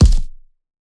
GDYN_Punching_Perc_PRO_SH - 1.wav